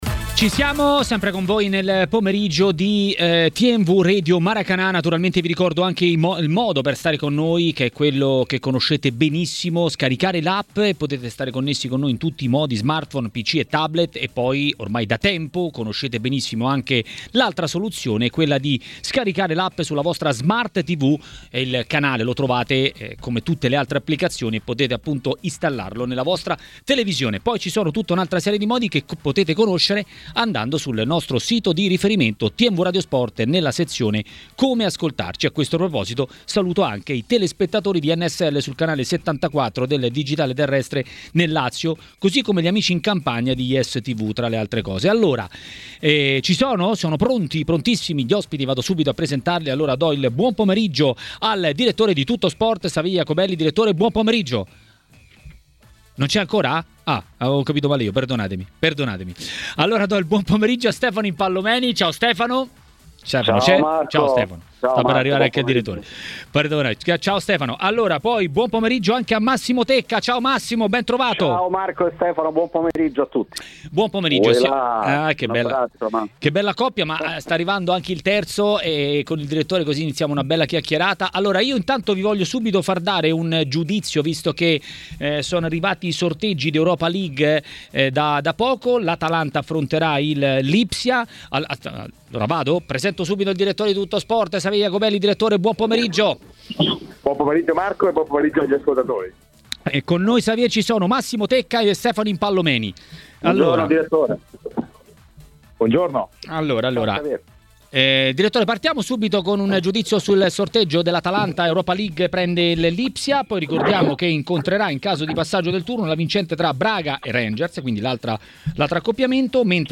Il giornalista ed ex calciatore Stefano Impallomeni a Maracanà, nel pomeriggio di TMW Radio, ha parlato delle italiane nelle coppe europee e non solo.